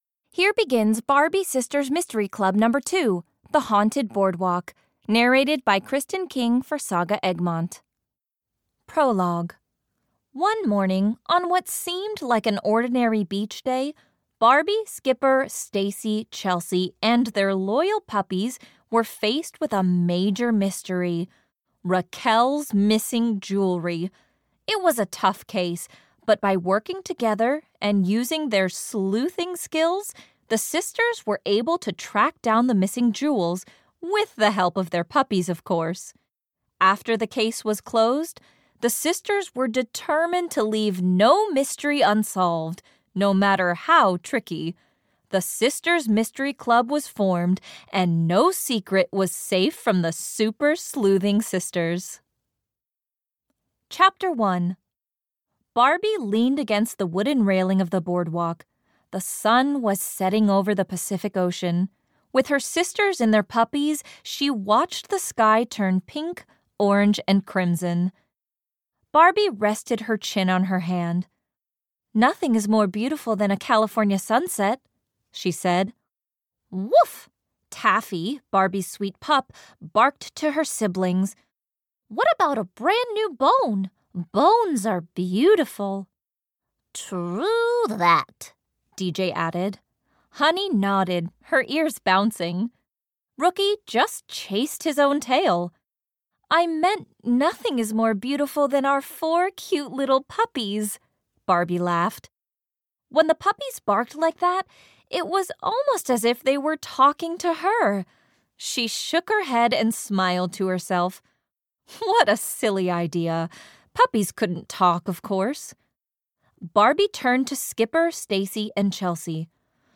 Barbie - Sisters Mystery Club 2 - The Haunted Boardwalk (EN) audiokniha
Ukázka z knihy